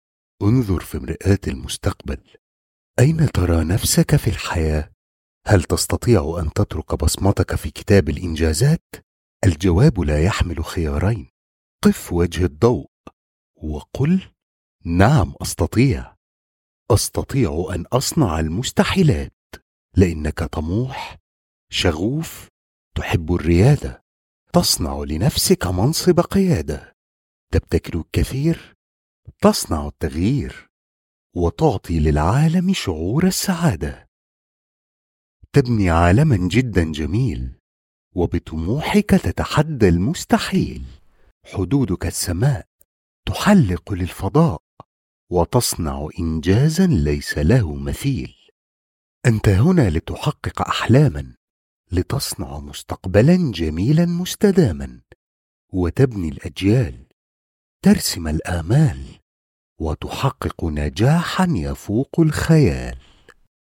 Male
Adult (30-50)
Narration
Arabic Poem